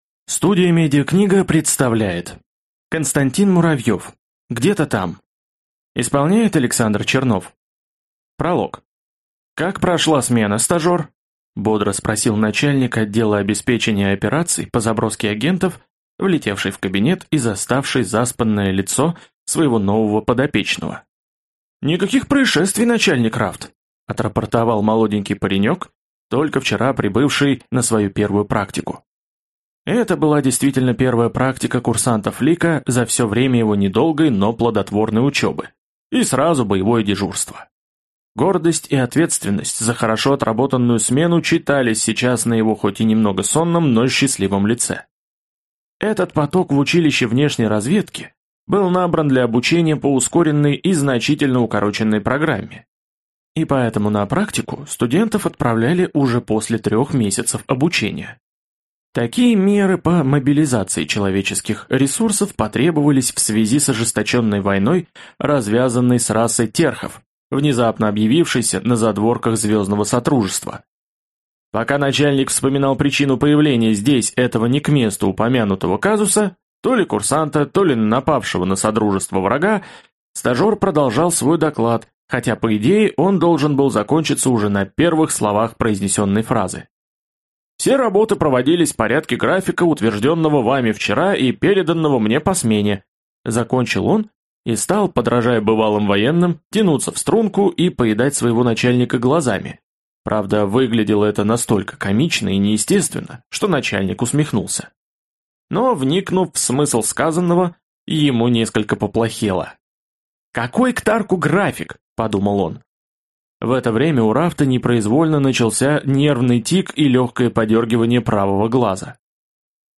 Аудиокнига Где-то там… | Библиотека аудиокниг